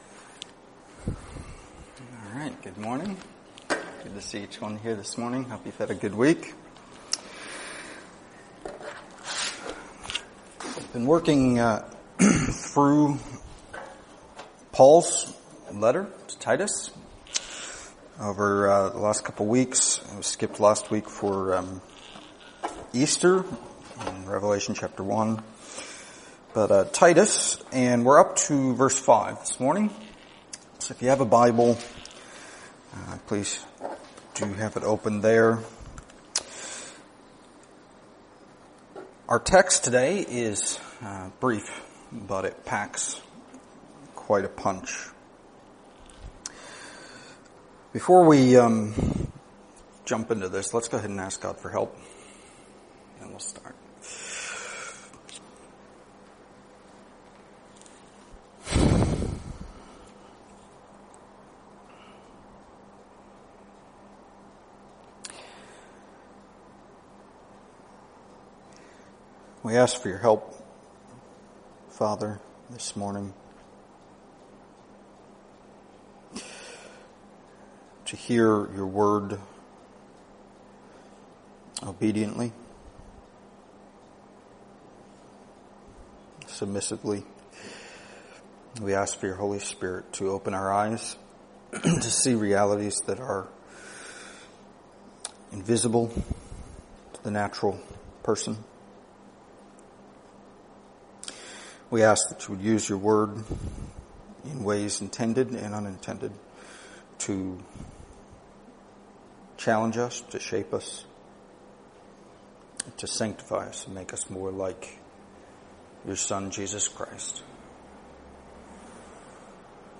Download mp3 Previous Sermon of This Series Next Sermon of This Series